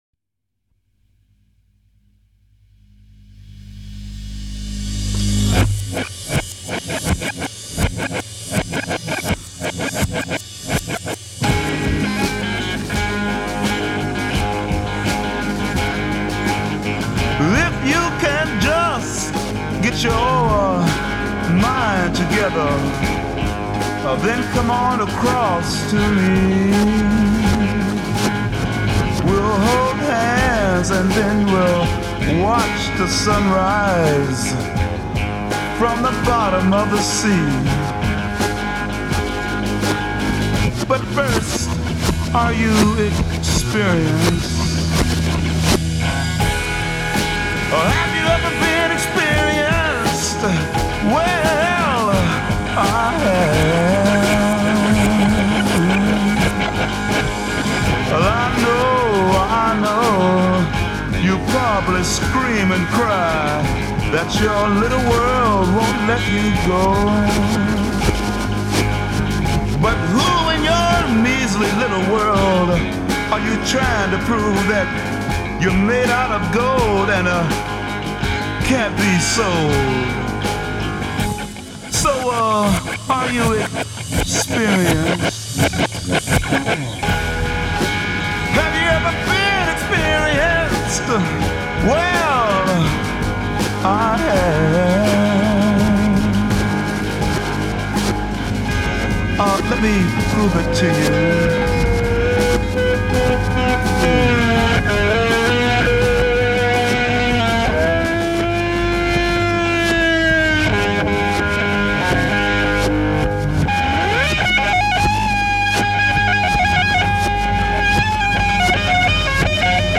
Recorded at Olympic Sound Studios, London, 3 April 1967.
vocal & guitar
piano
drums
Soli 26 Backwards guitar solo against ostinato.
Coda 15 fade out over ostinato with return fade in at end f
Transatlantic Psychedelic Blues